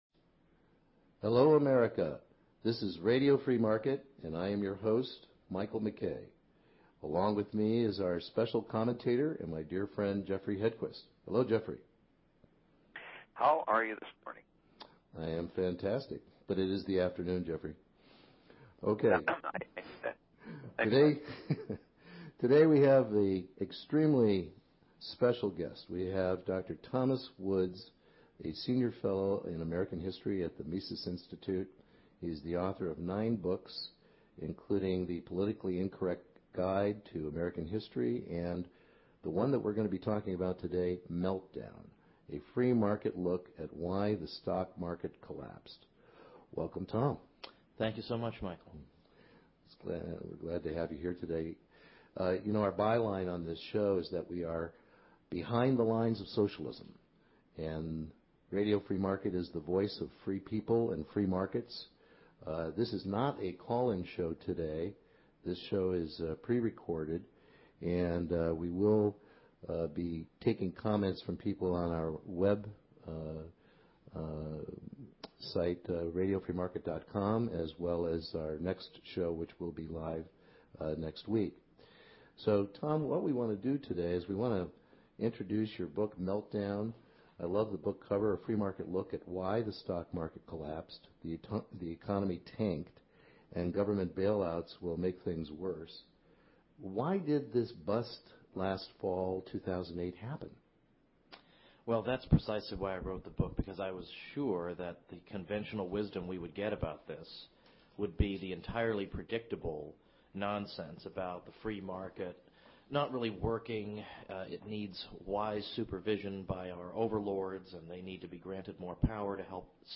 * Special Interview * with New York Times Best Selling Author Thomas E. Woods, Jr. about his book, Meltdown: A Free-Market Look at Why the Stock Market Collapsed, the Economy Tanked, and Government Bailouts Will Make Things Worse. Tom is a fabulous educator who clearly explains what really happened in the Fall of 2008; how the Economic Debacle was created and now is being made worse.